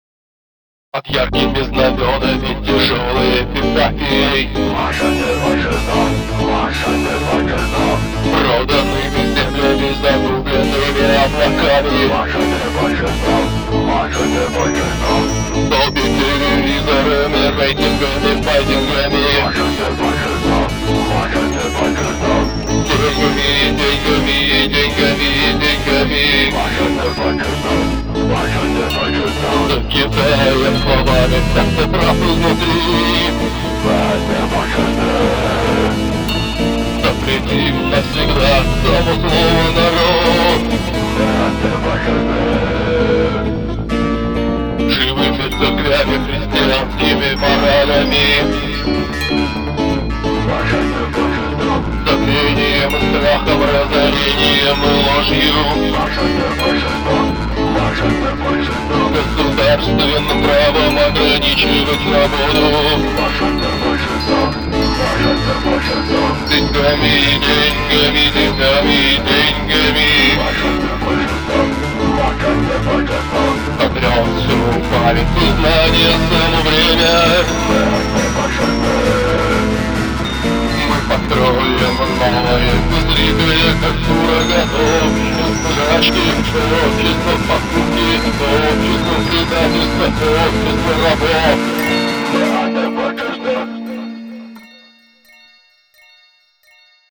Музыка и шумы
Гитары и вокал